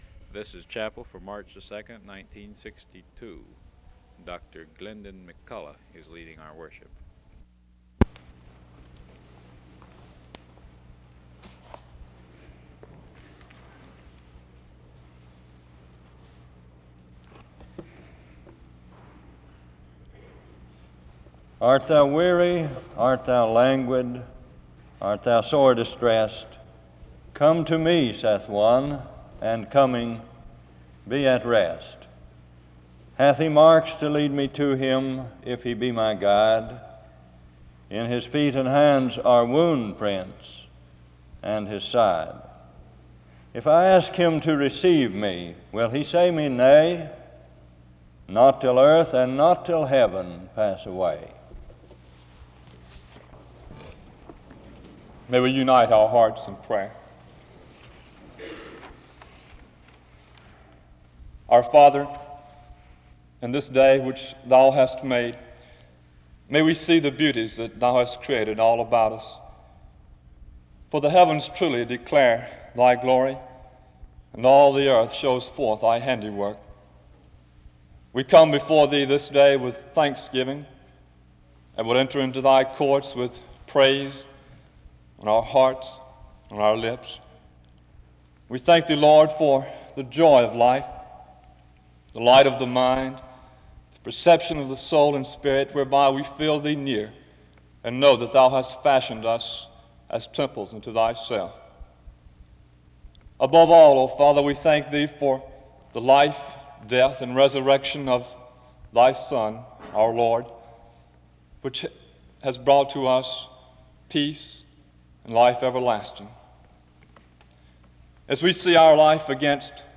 There is an opening prayer from 0:20-4:13.
SEBTS Chapel and Special Event Recordings SEBTS Chapel and Special Event Recordings